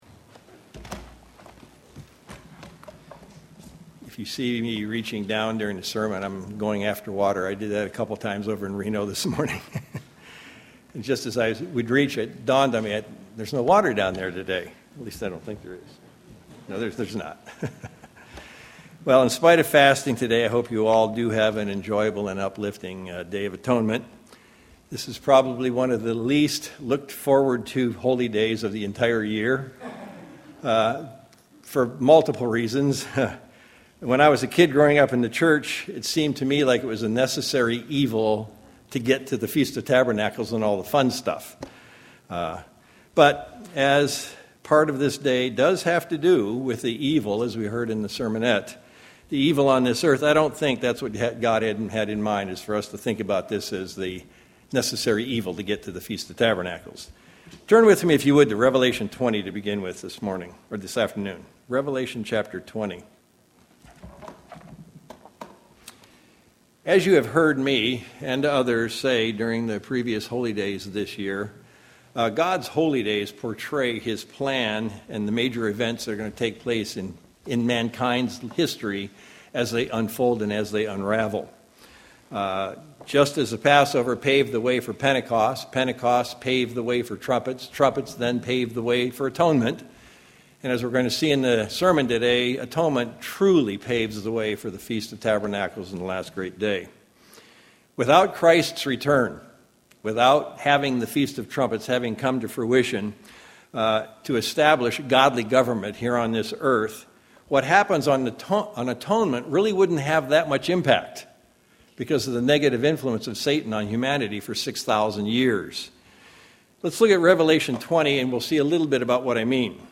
Given in Sacramento, CA
UCG Sermon Studying the bible?